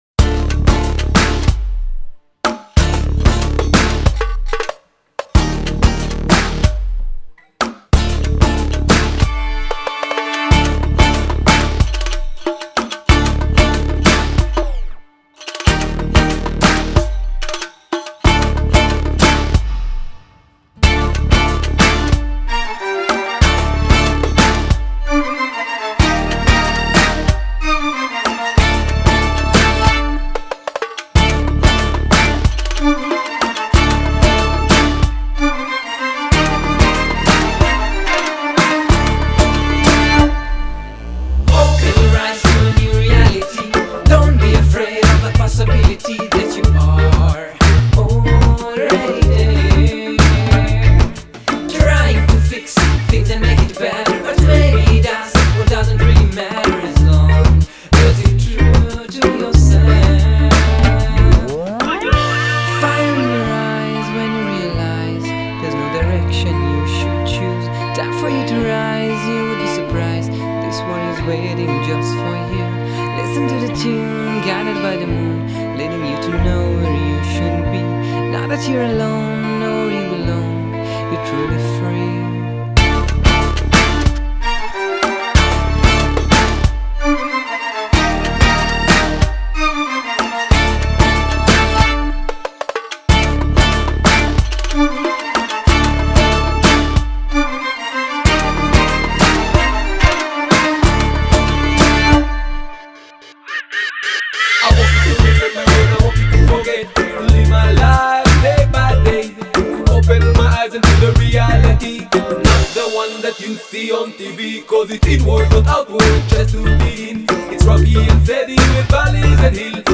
Electro – Ethnic – Reggae group
Genre: World